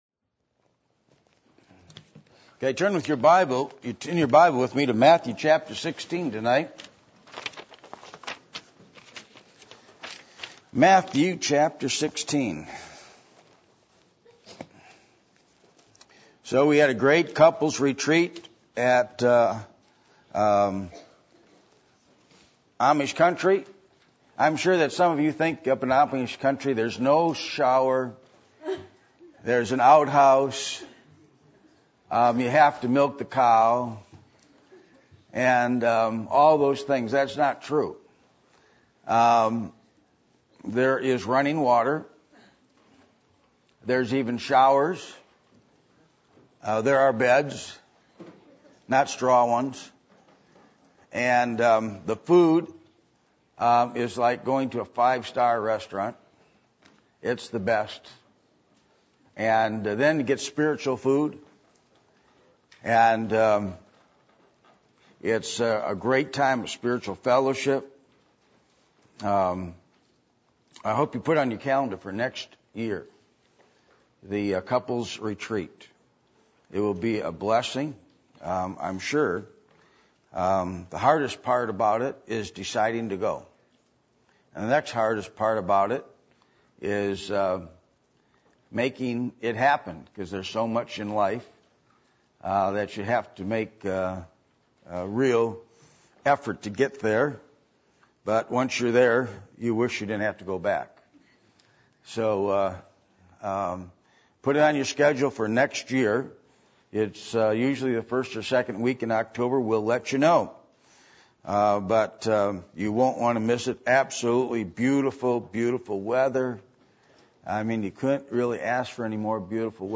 Acts 2:36-37 Service Type: Sunday Evening %todo_render% « Who Jesus Is Does Revival Come In Answer To Prayer?